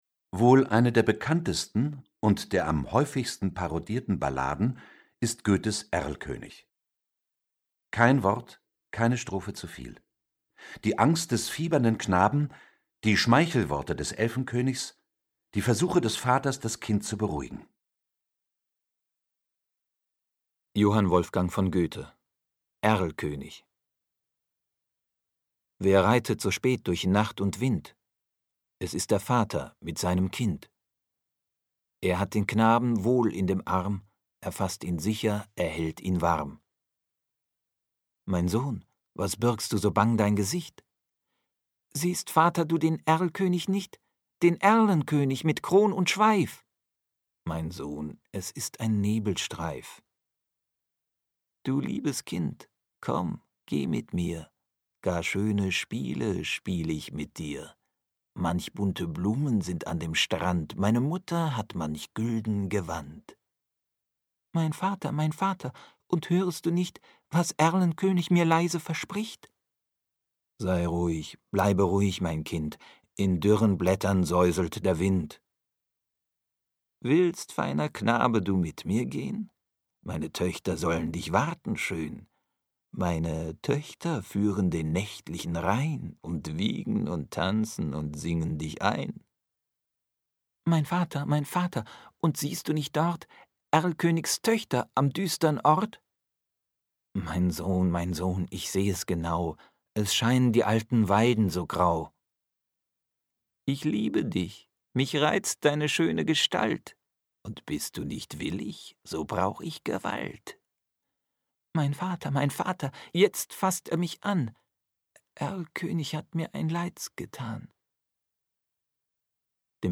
Schlagworte 95 Hörbuch • Balladen • Bilderbücher mit Erzähltexten • Goethe • Hörbuch; Lesung für Kinder/Jugendliche • Hörcompany • Literatur • Lyrik • Lyrik/Gedichte; Kinderbuch/Jugendbuch • Schicksalsballaden • Schiller